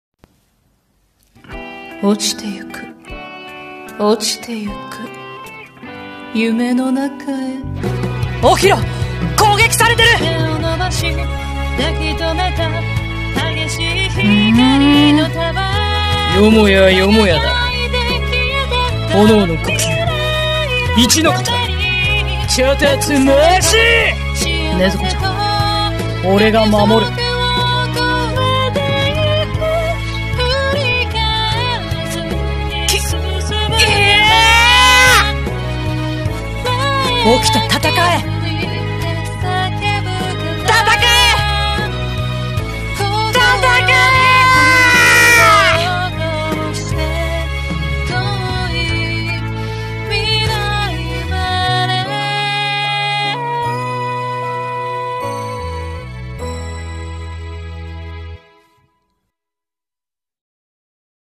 全部一人でやった鬼滅の刃 声劇 / 鬼滅の刃 無限列車編 予告編